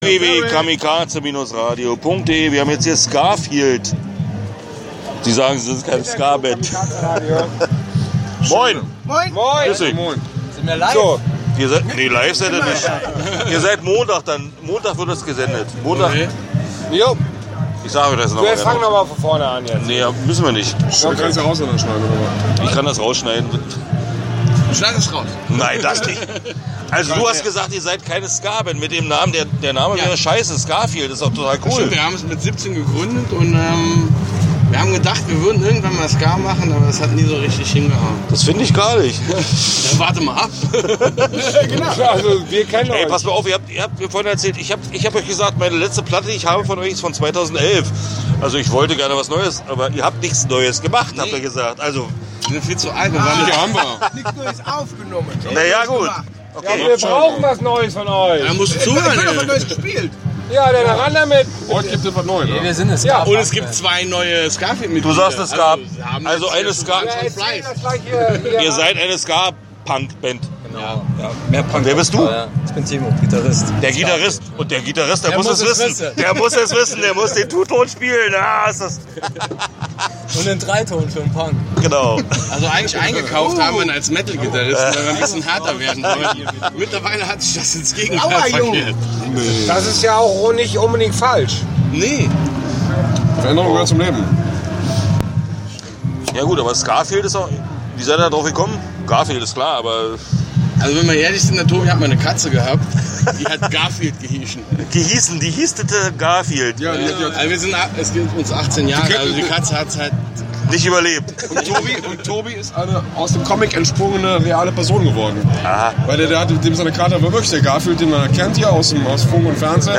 Hier drei Interviews.